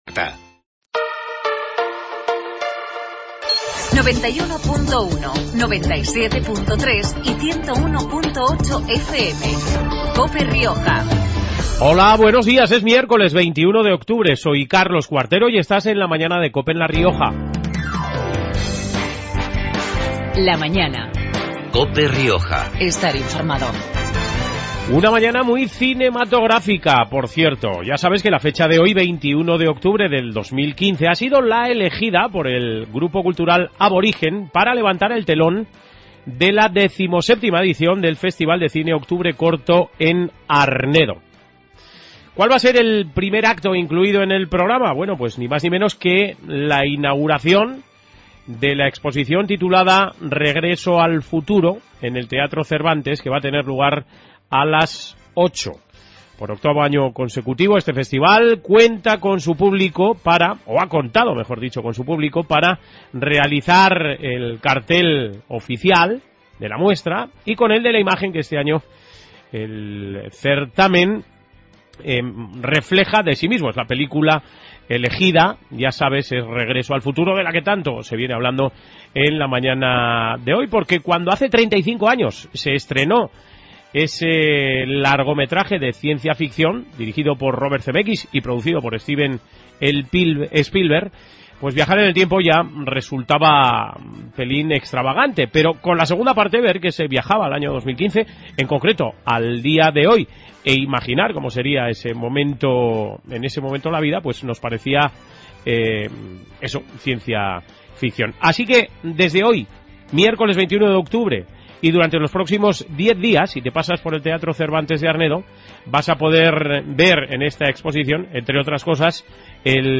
Programa regional de actualidad, entrevistas y entretenimiento.